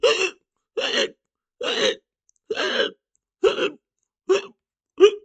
cartoon hic hiccups